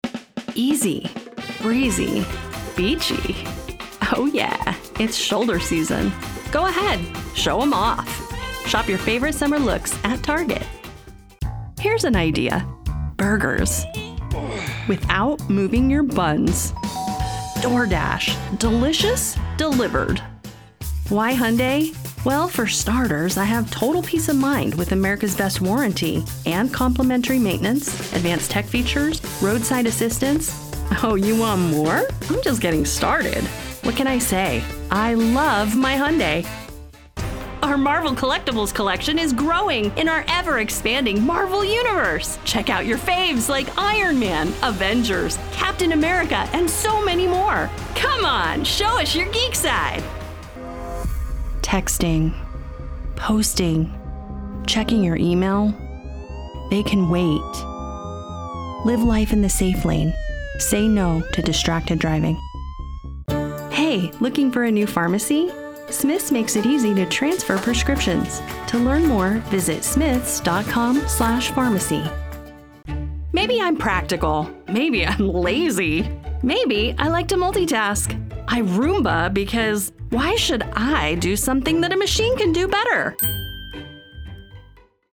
Commercial Demo Reel
English - USA and Canada